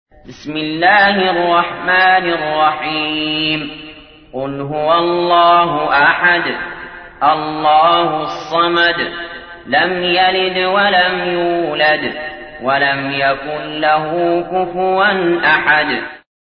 تحميل : 112. سورة الإخلاص / القارئ علي جابر / القرآن الكريم / موقع يا حسين